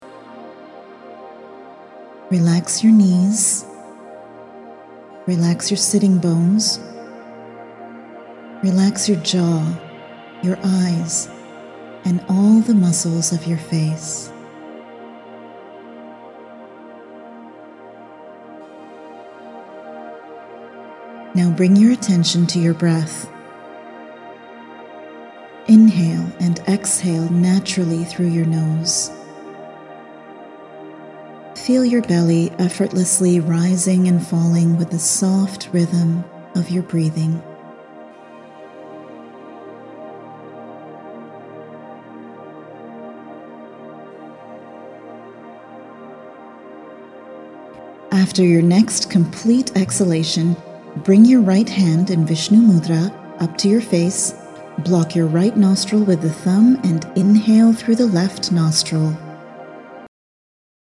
This 10-minute audio guided yogic breathing exercise is a must-have for your regular yoga and meditation practice. The download includes a beginner’s sequence of Anuloma Viloma (a.k.a. Alternate Nostril Breathing), which is an essential pranayama exercise in yoga.